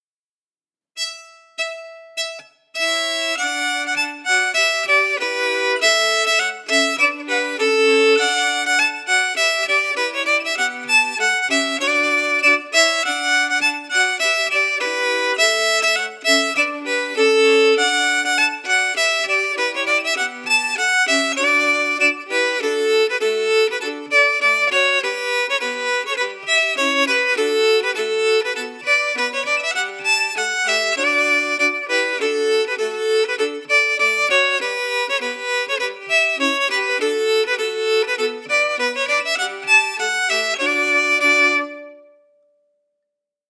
Key: D
Form: March or reel
Melody emphasis
Region: Scotland